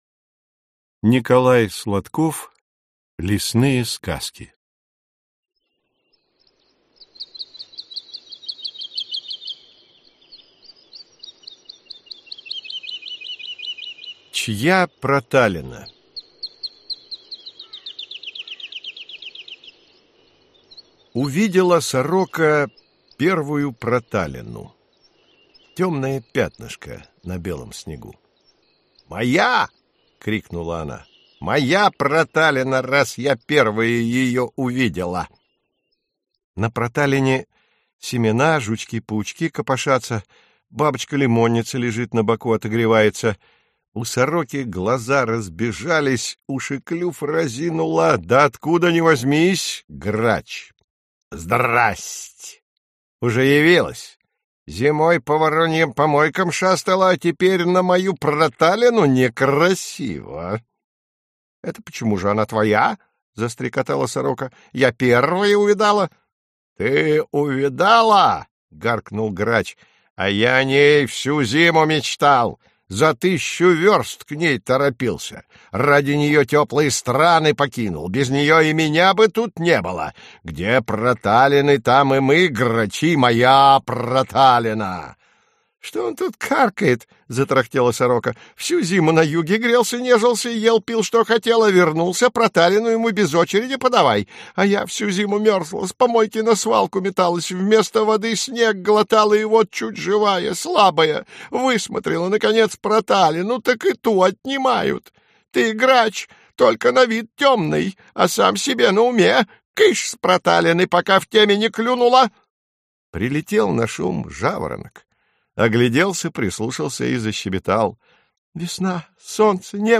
Аудиокнига Детям от 3 до 10 лет. Лесные сказки | Библиотека аудиокниг